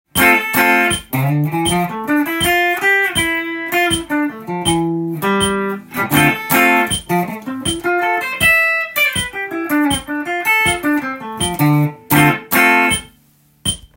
Dm7だけでギターソロ
Dm7のコードでもギターソロ練習出来ます。
Dドリアンスケールになります。
ドリアンスケールになると　かなりジャズっぽい感じに聞こえますので